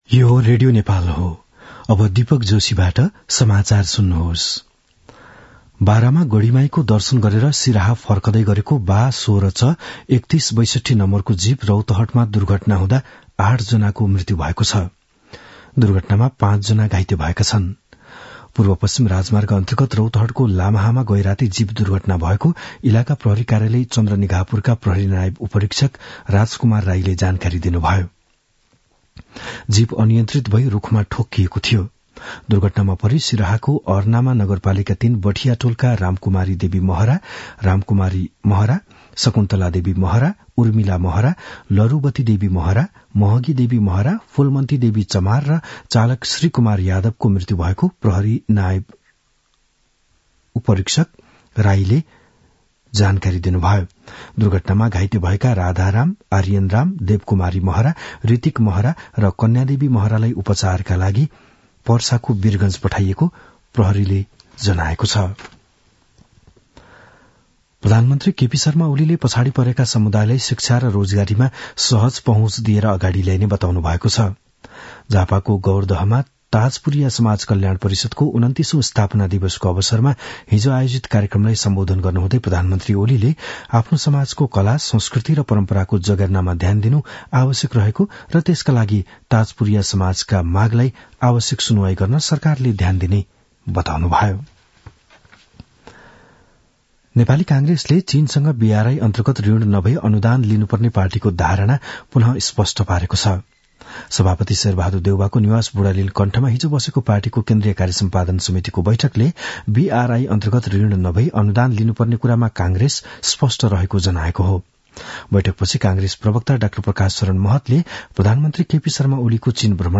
बिहान ११ बजेको नेपाली समाचार : २६ मंसिर , २०८१
11-am-nepali-news-1-8.mp3